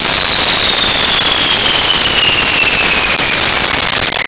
Bomb Fall 01
Bomb Fall 01.wav